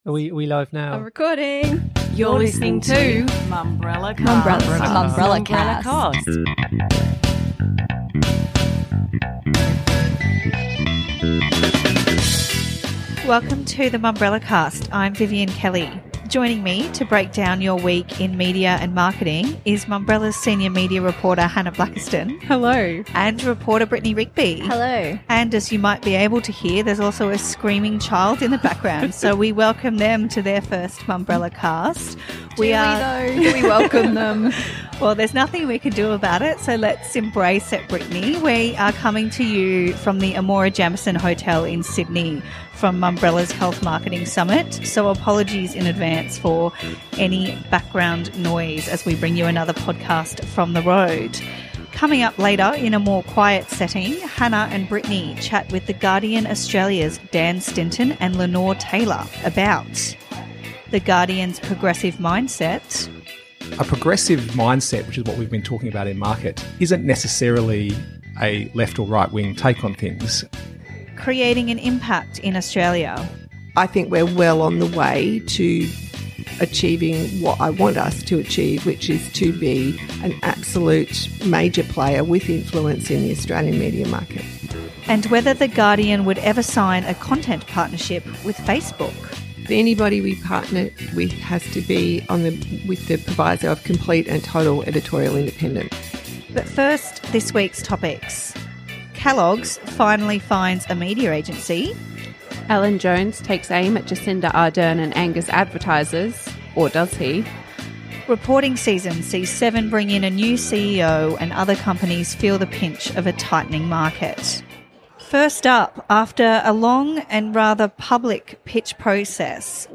The team sat down to discuss if the advertisers were really gone for good, and if Jones would actually get axed in the inevitable reoccurrence of this event – like 2GB has threatened.